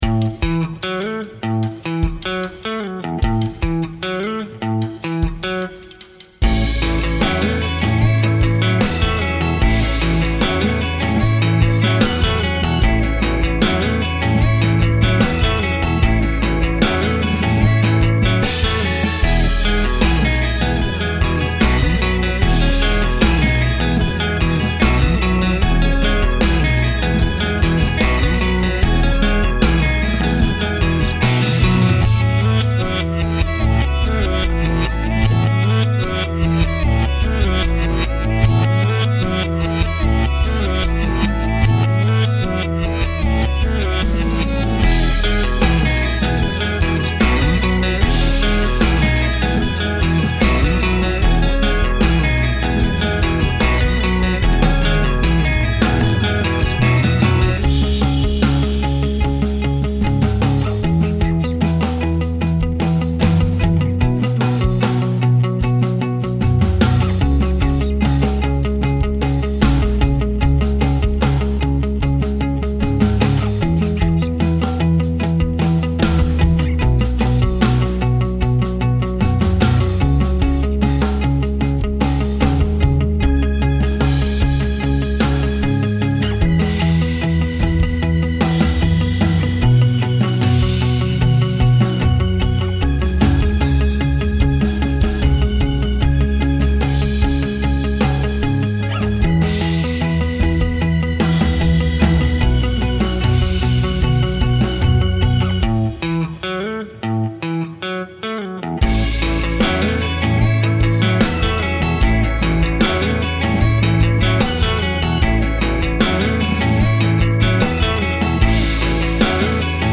is a rock song with aggressive vibes
150 BPM
rock aggressive uplifting electric guitar bass drums organ